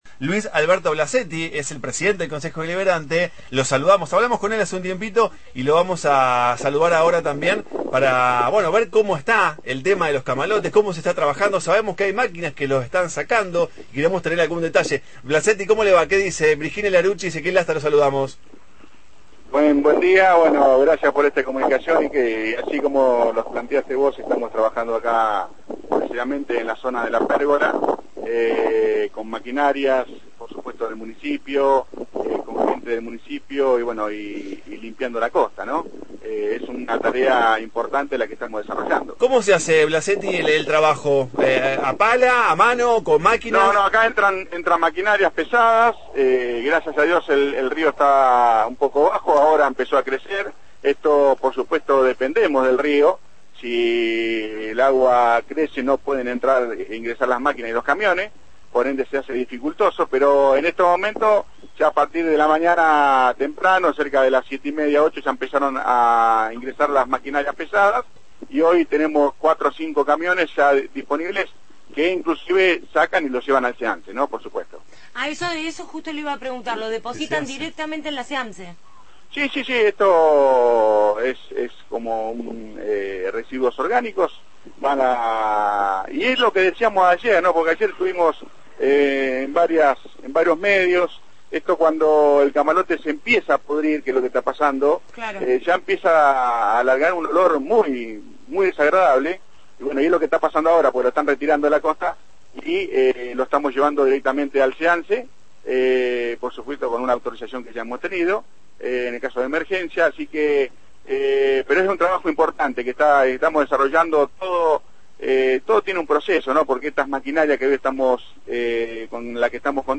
Luis Blasetti, presidente del Concejo Deliberante de Ensenada, quien está a cargo de la intendecia de manera temporal, dialogó